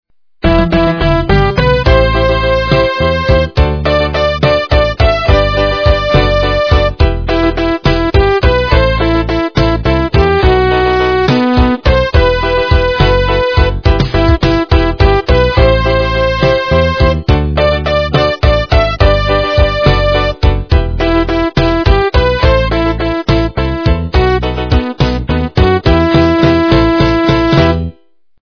полифоническую мелодию